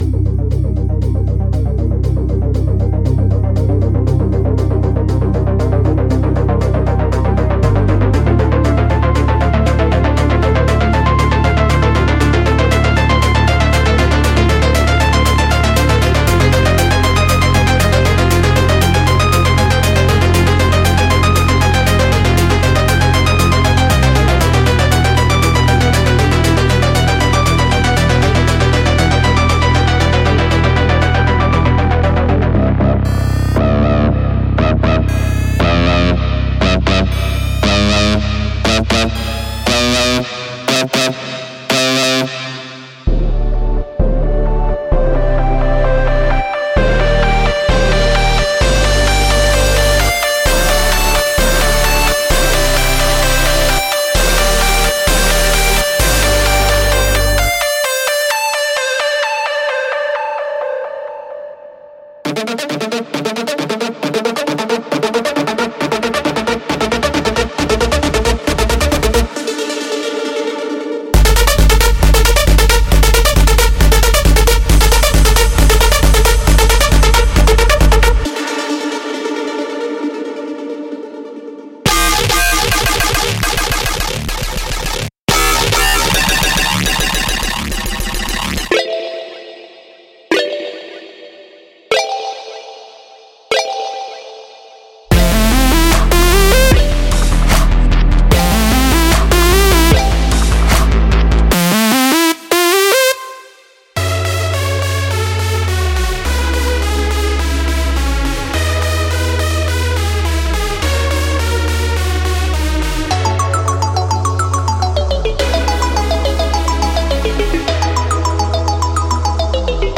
To celebrate we’ve put together a diverse collection of over 100 presets for Serum, Spire, and Sylenth1.
• BASS (14)
• CHORD (4)
• LEAD (11)
• PLUCK (5)
• ARP (3)